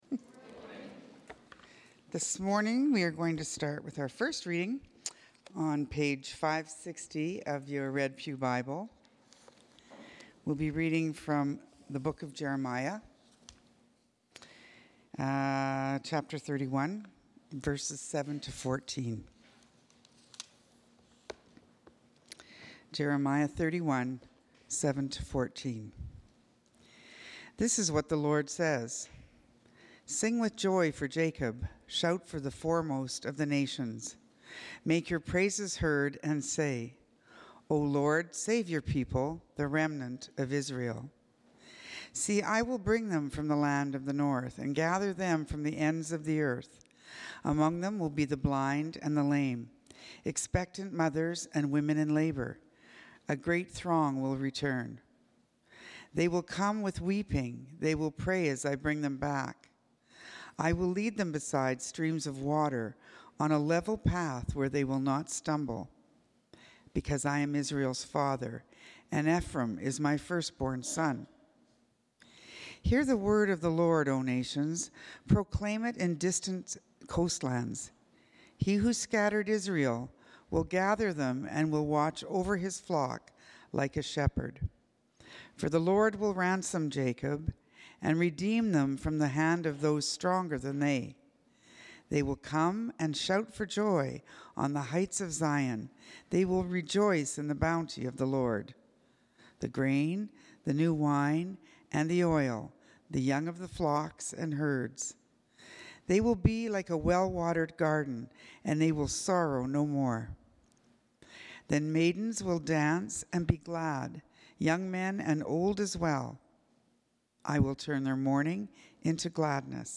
Sermons | St. Paul's Anglican Church